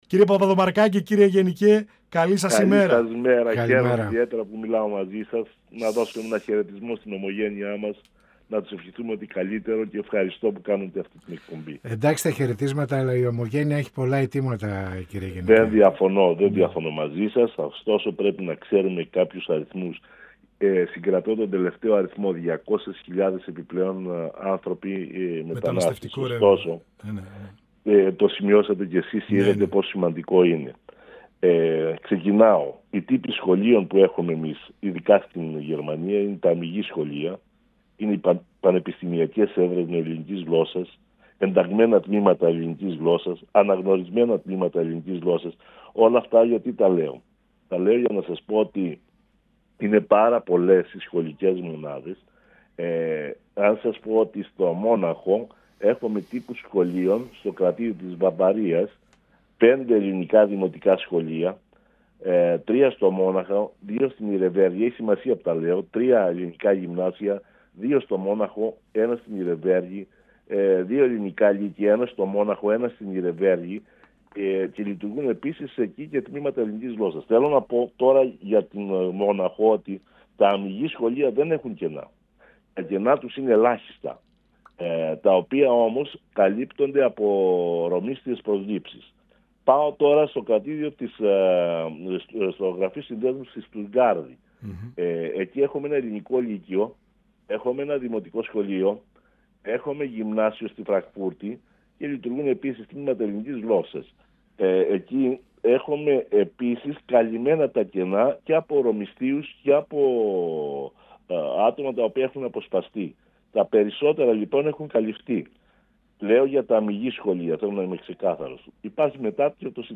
Στην απομείωση των τμημάτων ελληνικής γλώσσας και στην κατάρρευση του αριθμού των Ελλήνων εκπαιδευτικών που από 500 έχουν απομείνει 15 στα εναπομείναντα σχολεία στο κρατίδιο της Βάδης-Βυτεμβέργης, όπως αναφέρουν οι εκπρόσωποι των Ομογενώναπό τη Γερμανία αναφέρθηκε ο Γενικός Γραμματέας του Υπουργείου Παιδείας Γιάννης Παπαδομαρκάκης, μιλώντας στην εκπομπή «Πανόραμα Επικαιρότητας» του 102FM της ΕΡΤ3.